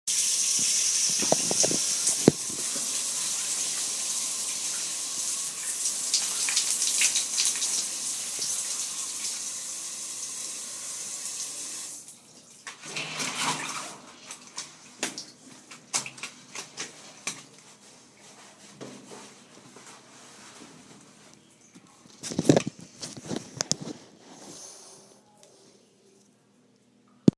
Location: The bathroom
Sounds: Shower running, shower being turned off, curtain opening